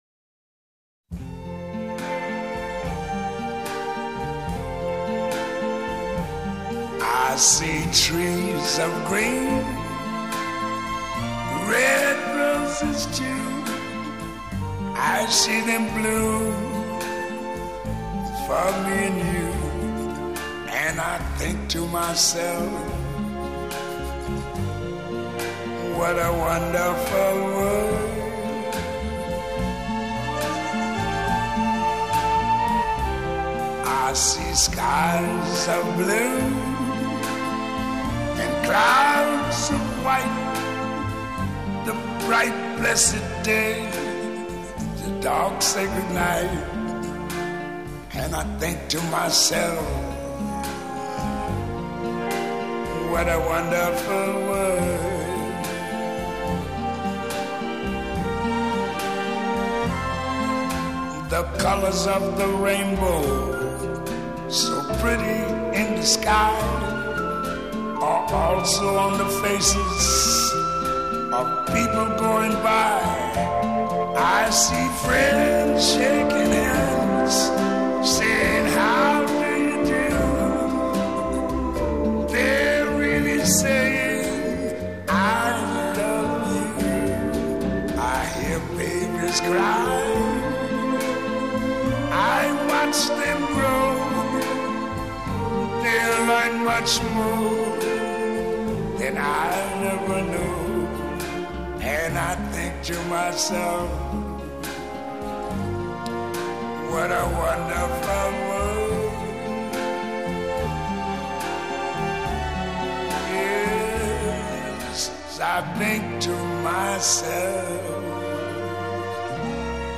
Genre: Vocal.